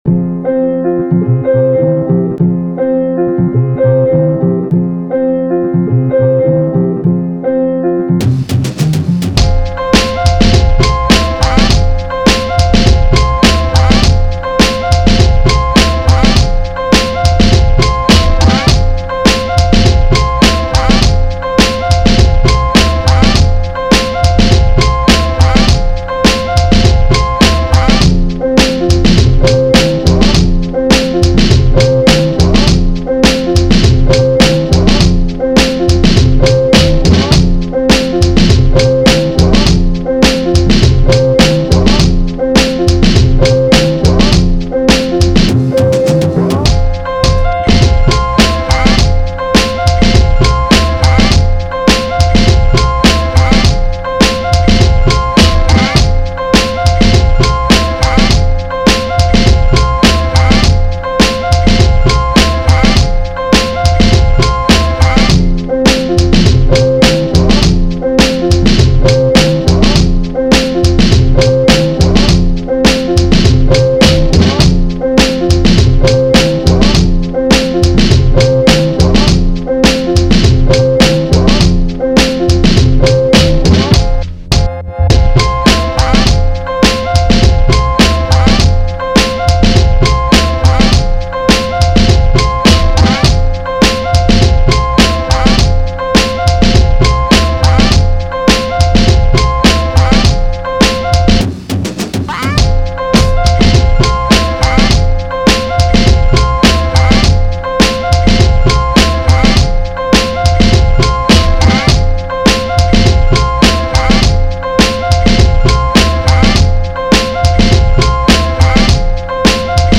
90s, Hip Hop
F major